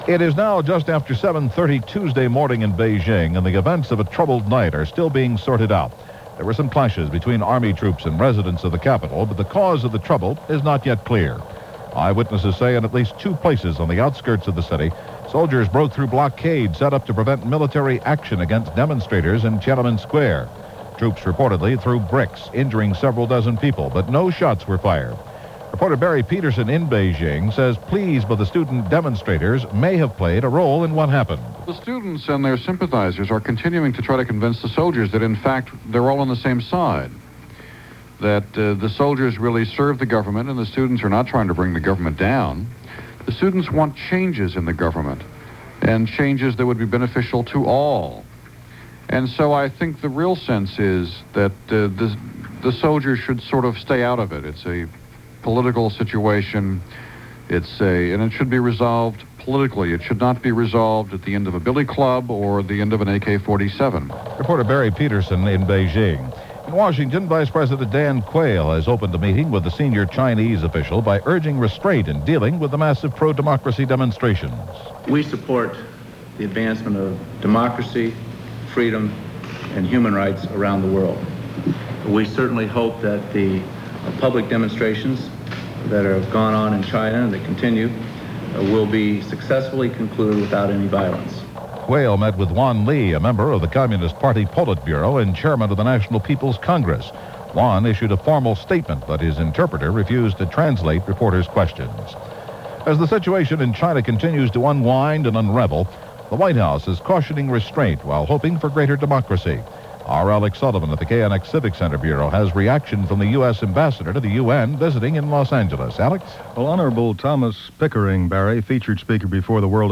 CBS Radio News On The Hour